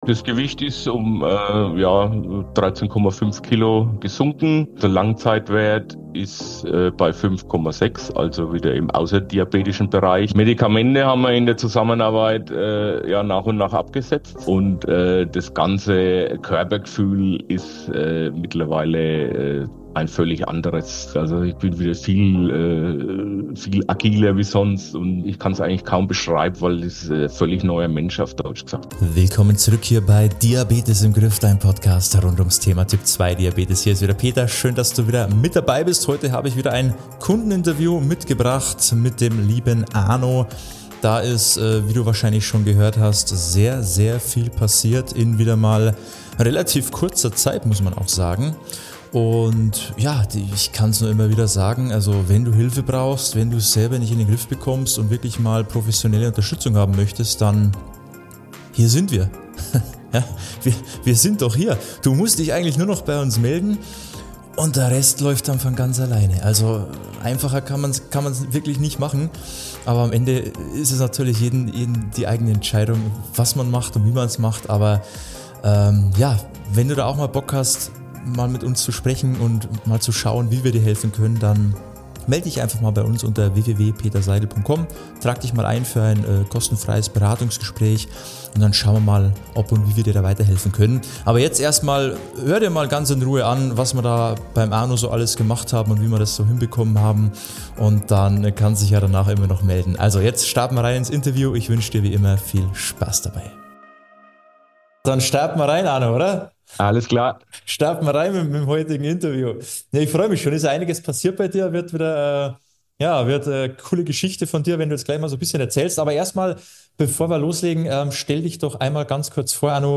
Ein starkes Beispiel dafür, was möglich ist, wenn Ernährung gezielt und individuell angepasst wird. Viel Freude mit diesem motivierenden Interview!